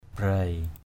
/braɪ/